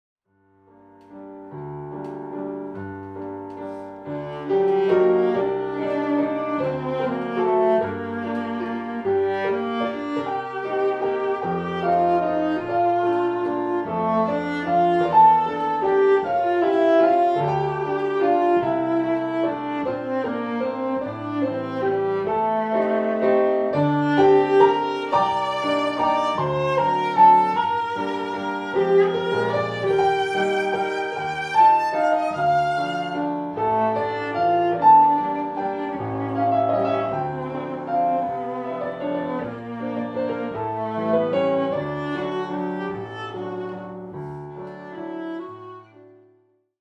Please find down below a few extracts from the show.